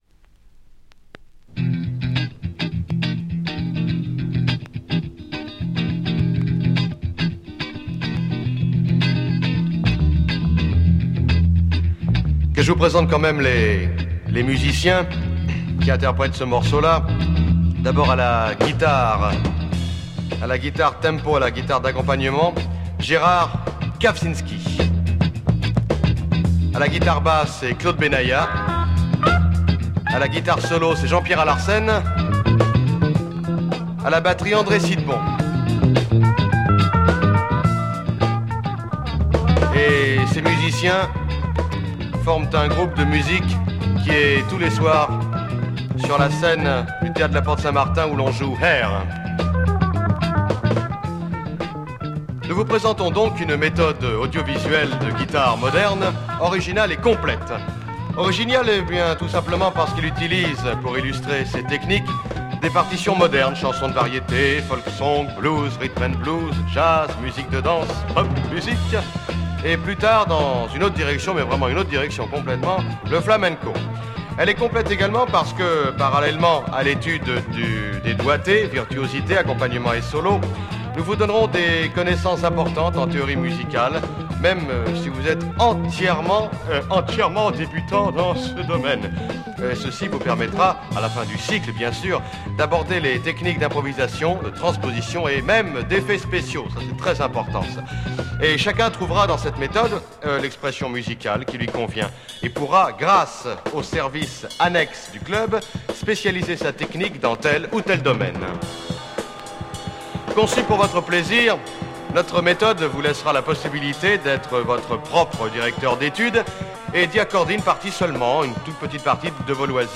French vocal Progressive sike